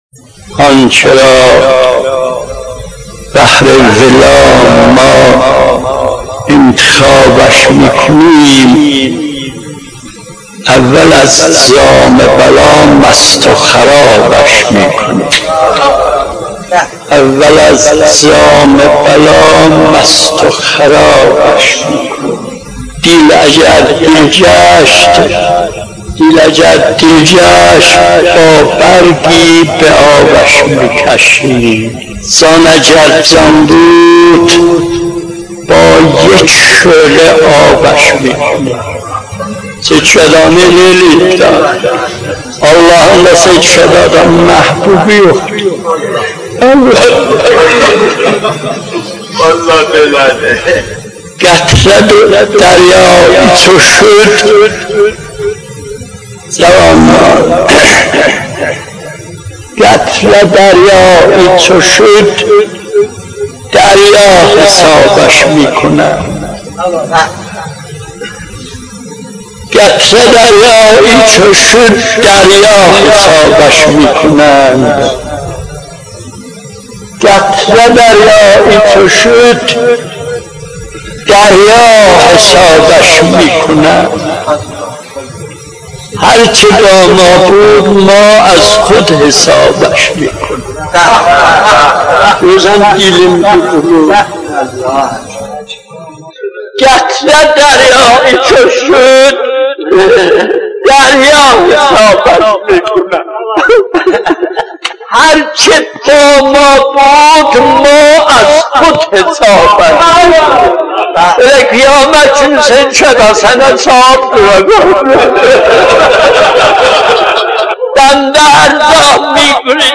مناجات10.mp3